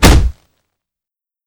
Punch_Hit_43.wav